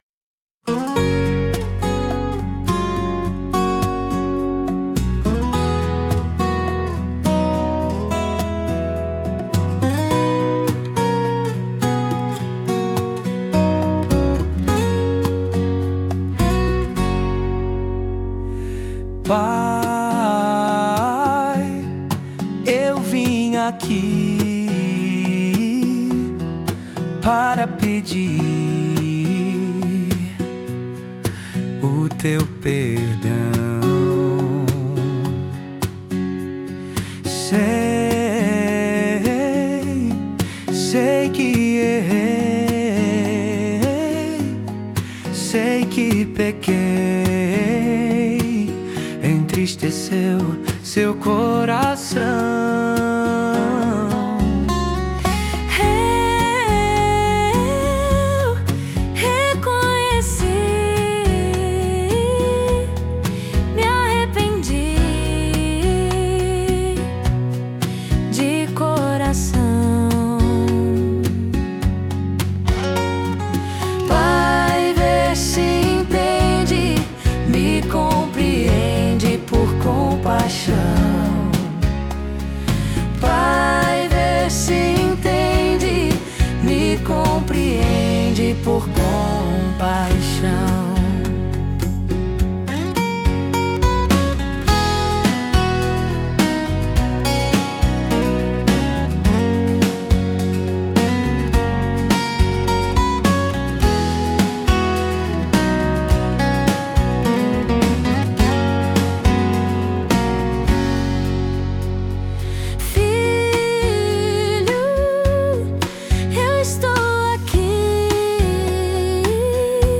[Instrumental Intro]
[Vocal Masculino]
[Instrumental Solo] [Verso 4] [Excitação Verso] [Vocal Masculino] Filho, eu estou aqui, Para te ouvir, Com atenção.